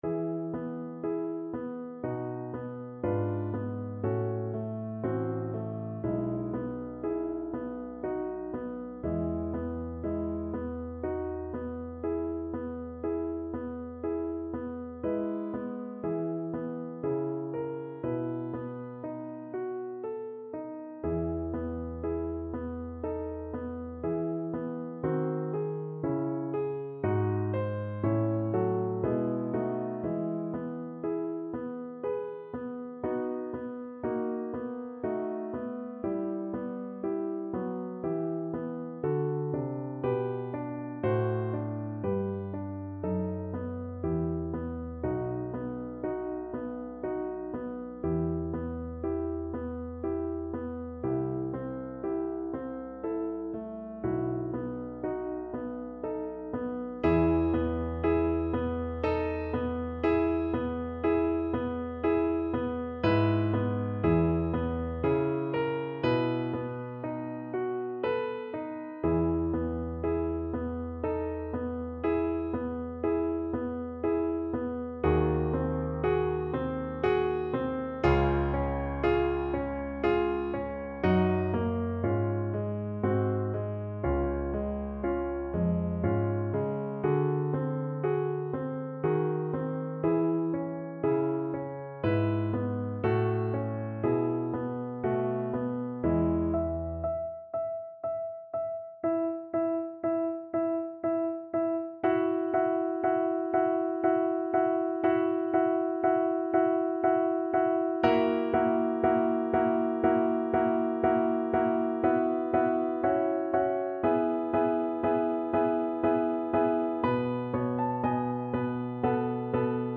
3/4 (View more 3/4 Music)
Adagio
Classical (View more Classical Cello Music)